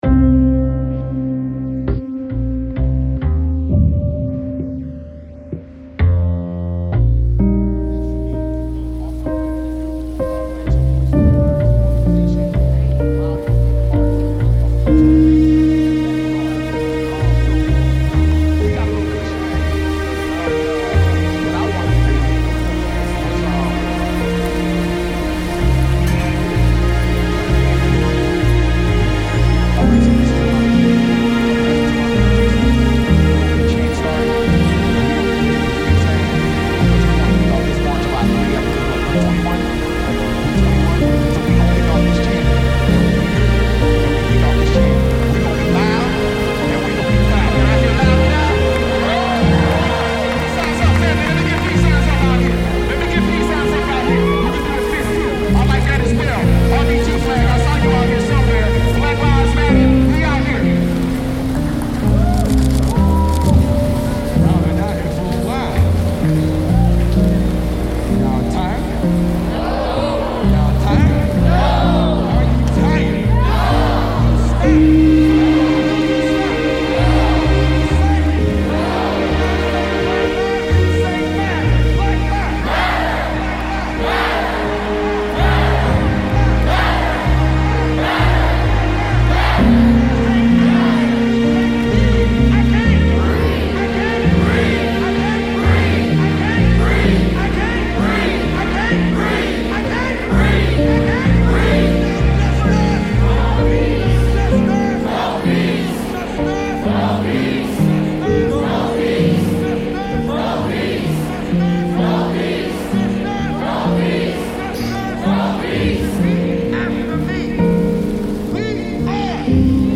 Orlando Black Lives Matter protest reimagined